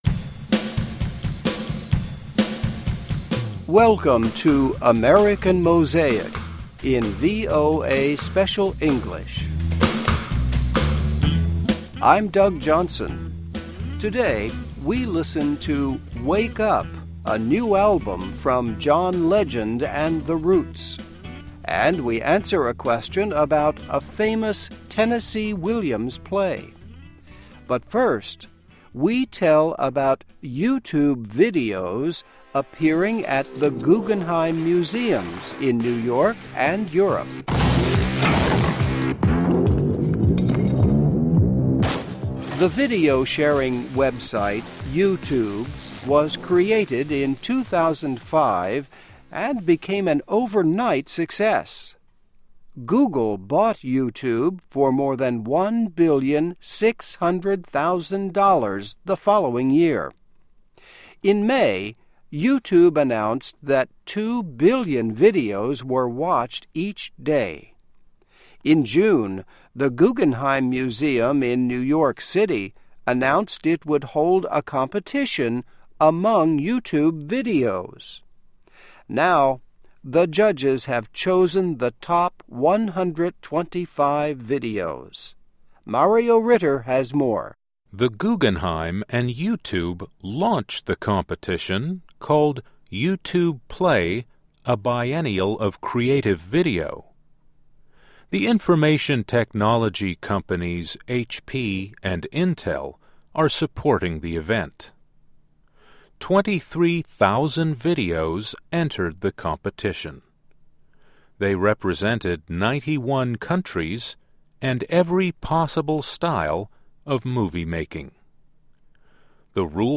Welcome to American Mosaic in VOA Special English.